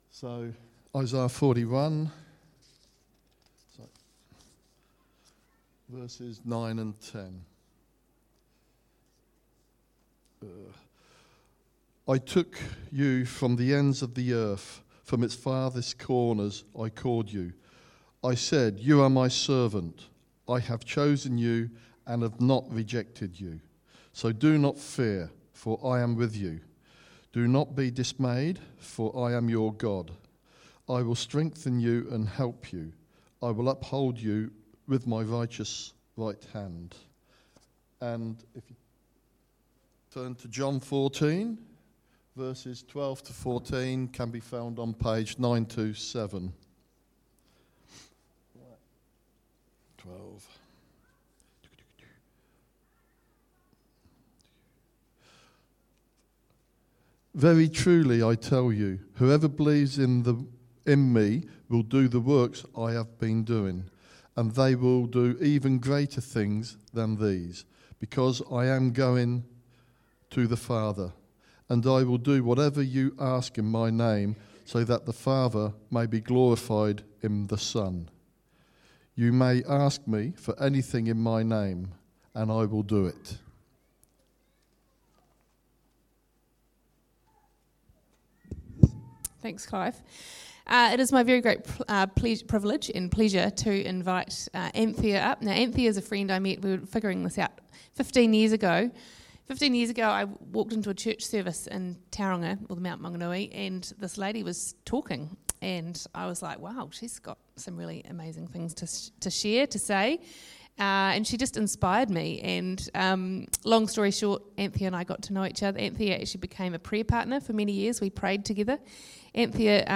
(Sorry the recording was cut off unexpectedly so missing the end of this talk)